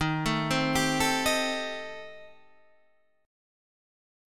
D#6b5 chord